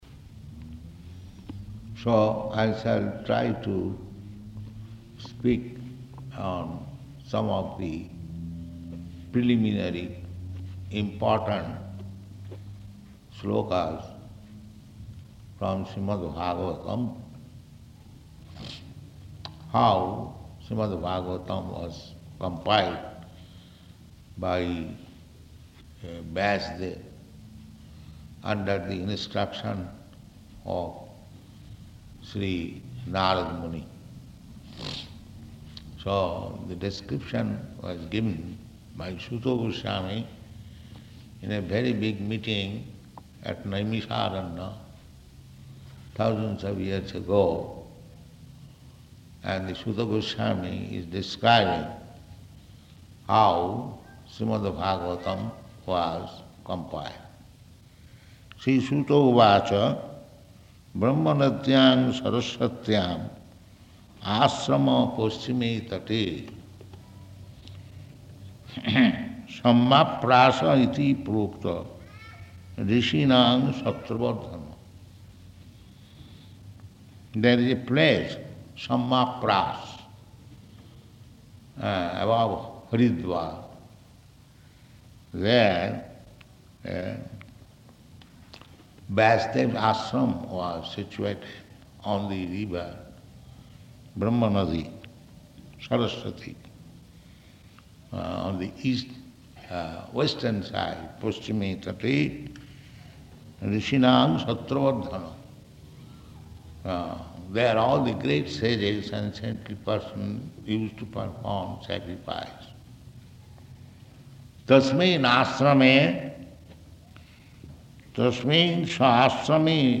Location: Johannesburg